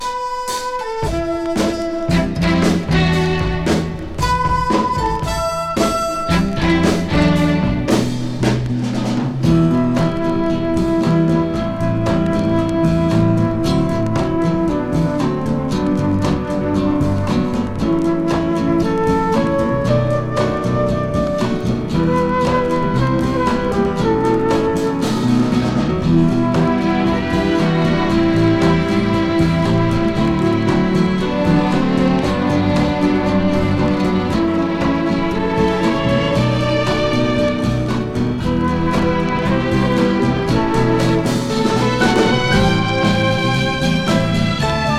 めくるめく美メロ連発の演奏に誘われ、良質さに打たれます。
Pop　USA　12inchレコード　33rpm　Stereo